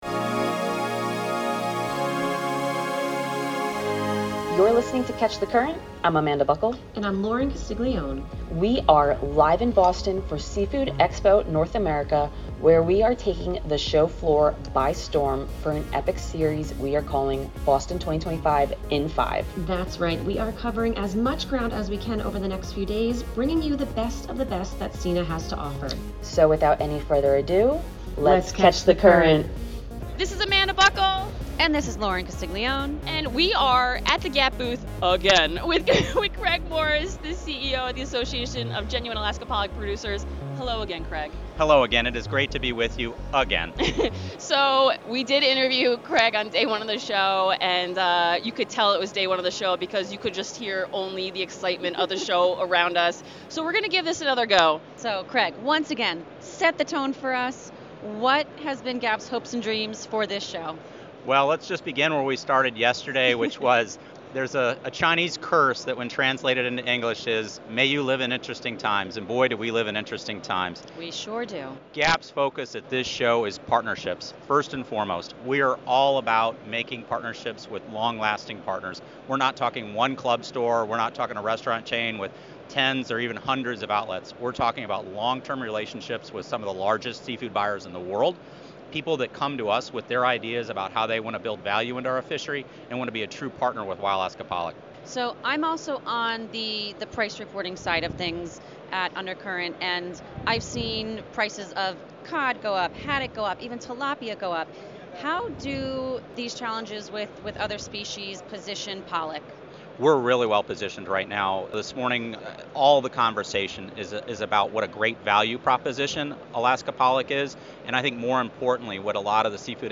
live on the show floor at Seafood Expo North America in Boston